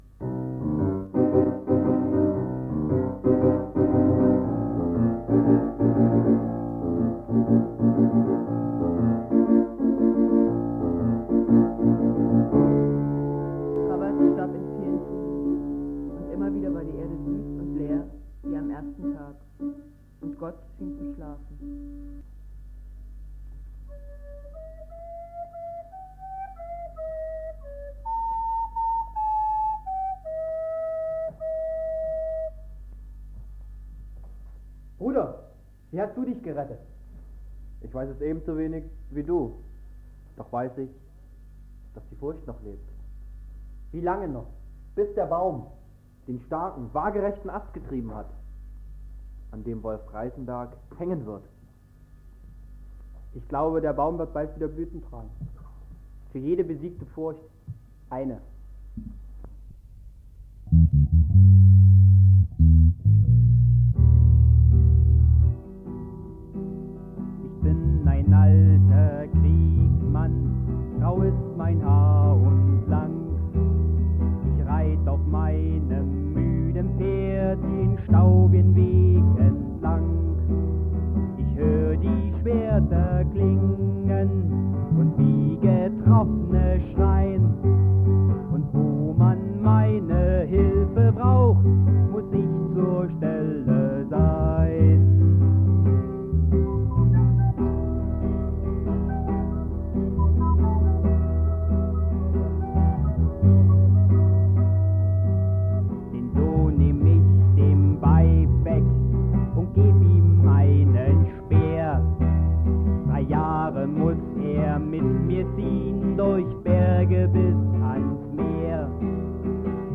Text Sprecher, Klavier
Gesang, Sprecher, Flöte
Sprecher, Bassgitarre